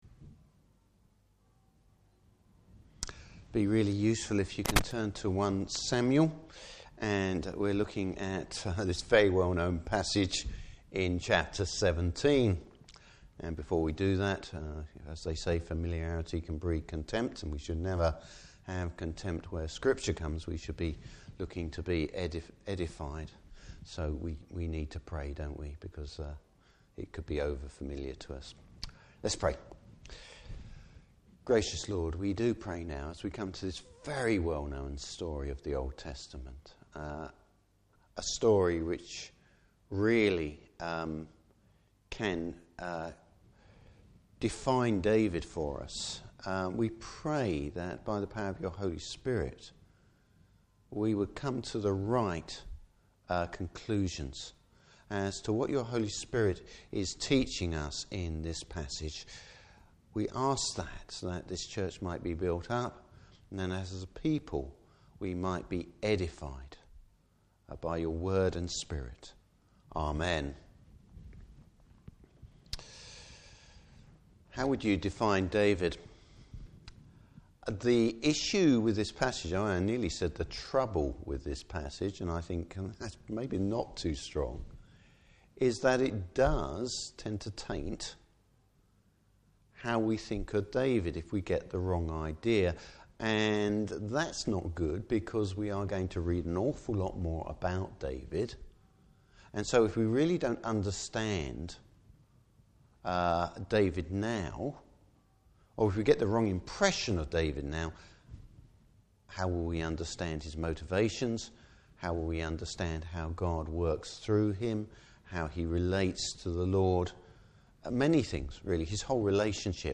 Service Type: Evening Service How do we define David?